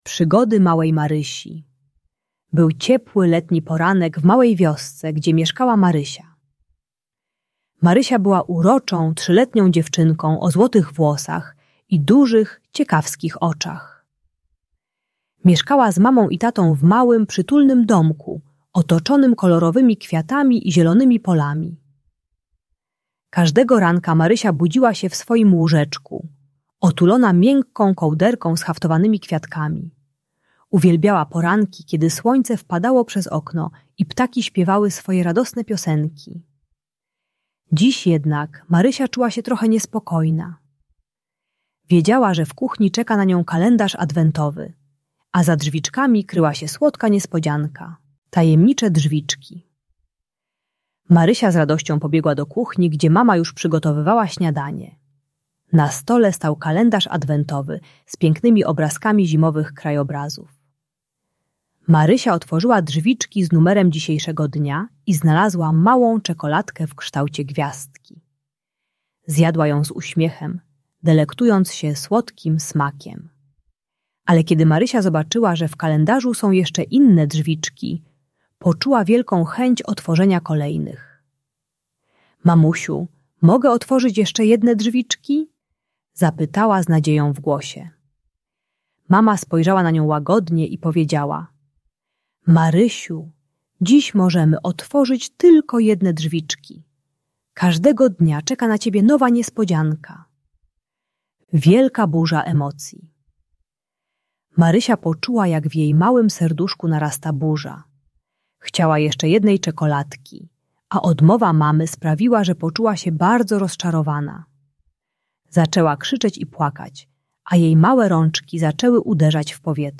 Mała Marysia uczy się techniki "magicznego kamienia" - przytulania uspokajającego przedmiotu i myślenia o pięknych rzeczach, gdy narasta frustracja. Audiobajka o radzeniu sobie ze złością dla przedszkolaka.